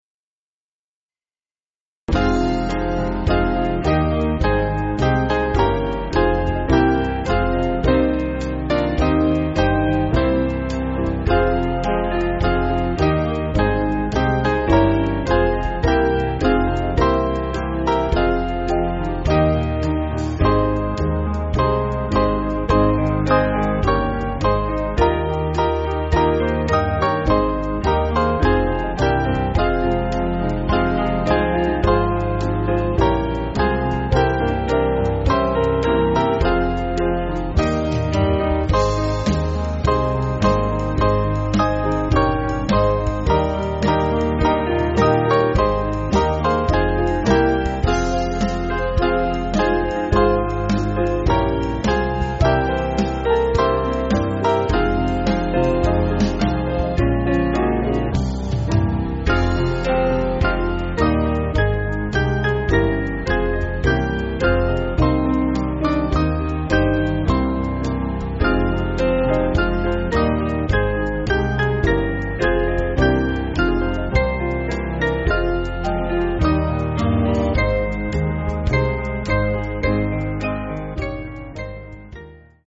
Small Band
(CM)   3/Fm